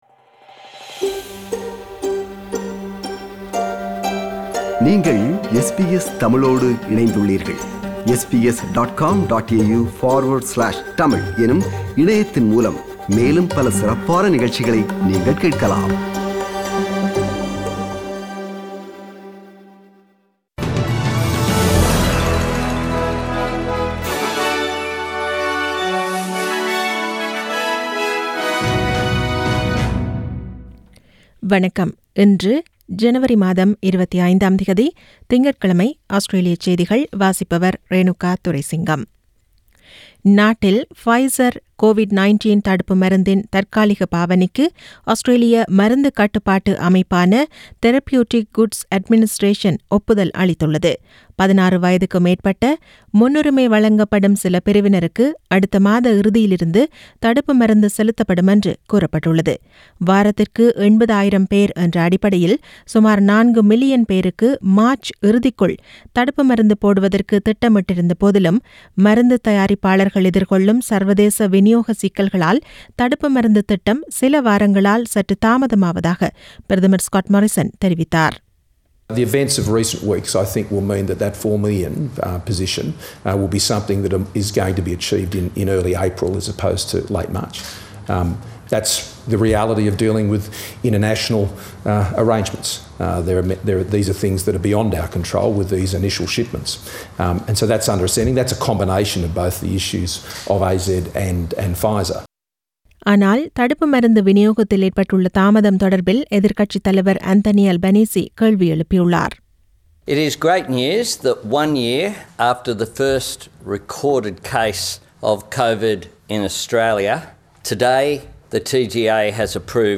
Australian news bulletin for Monday 25 January 2021.